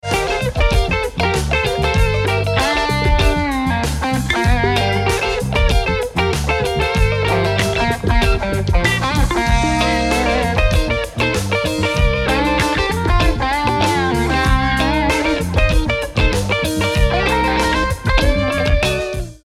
rock instrumental à la guitare
Guitare électrique
Batterie
Basse